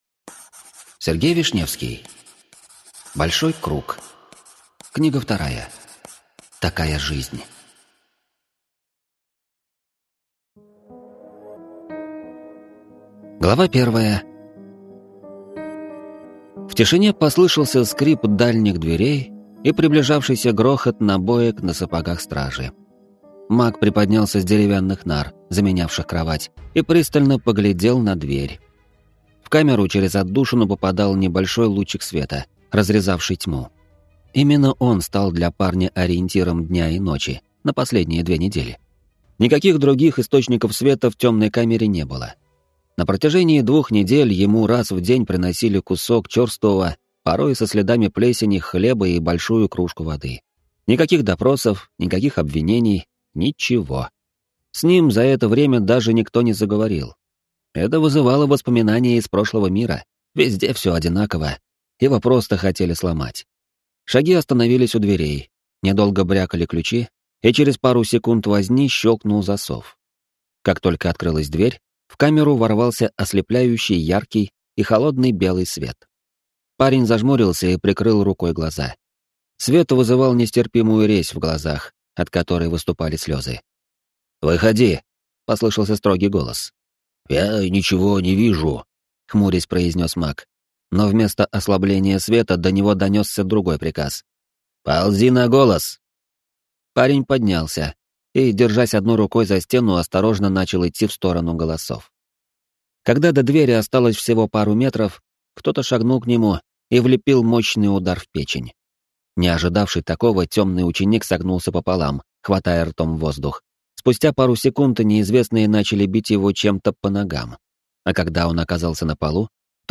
Аудиокнига Большой круг: Такая жизнь | Библиотека аудиокниг